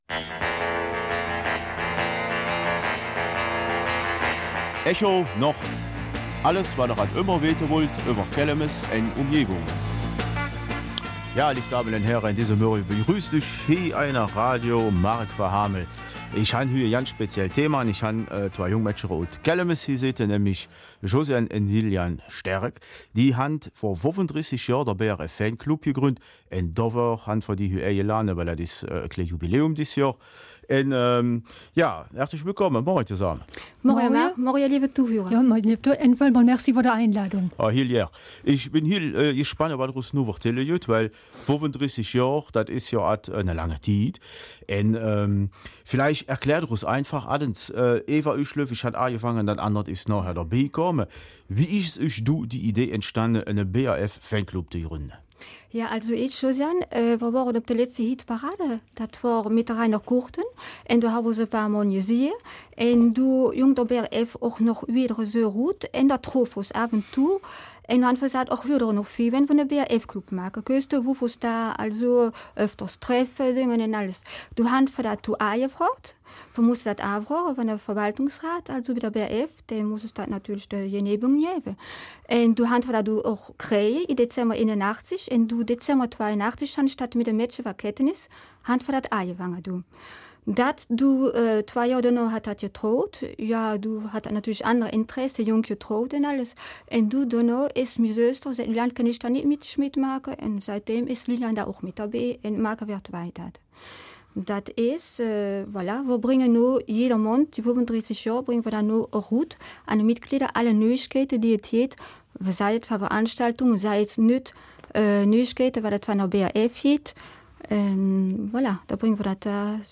Kelmiser Mundart: 35 Jahre Betreuung des BRF-Fanclubs